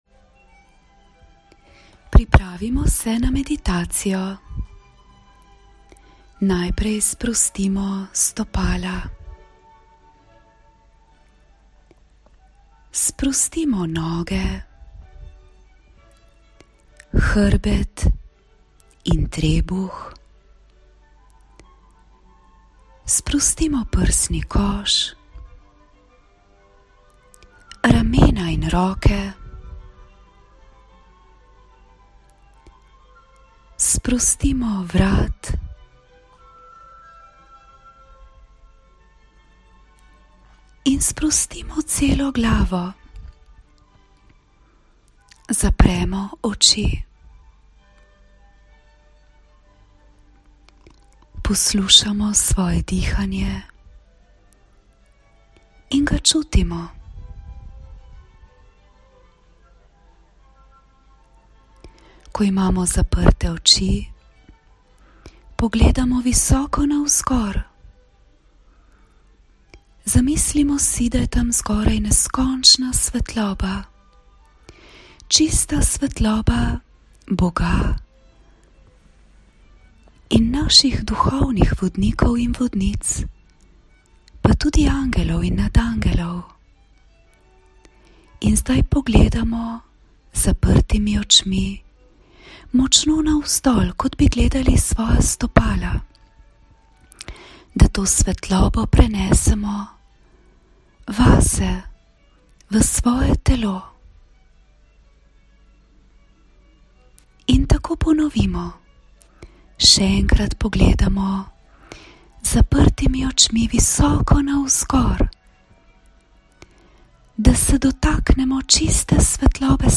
Meditacija: Stik z Nadzavestjo, Lastna Vrednost in Možgani
Zvočni posnetek spremlja komaj slišna Vivaldijeva skladba, ki z visoko frekvenco povezuje z Nadzavestjo.
Primerna je za vse otroke od 6.leta starosti do 14.leta, seveda deluje enako intenzivno tudi za starejše, tudi za odrasle, a je besedišče prilagojeno mlajšim.